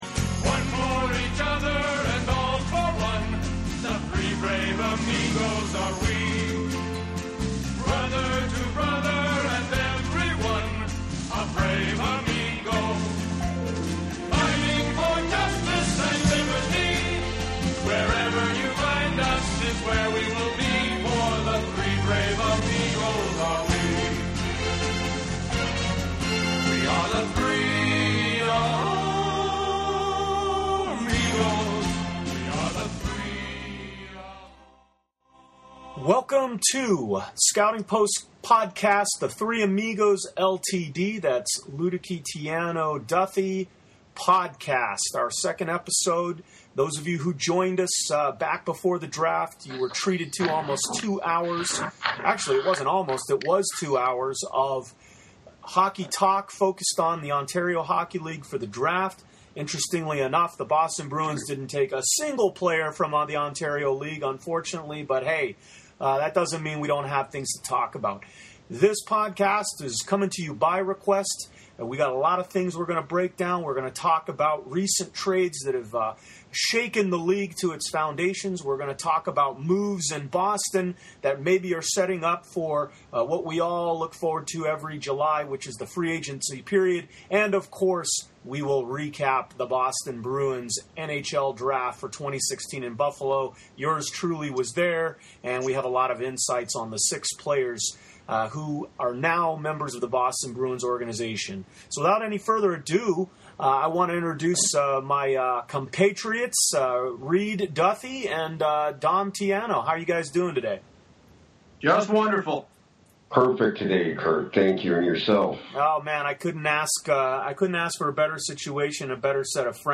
Everyone’s a loser because we don’t exactly get every word he says, but he brings plenty of great insights.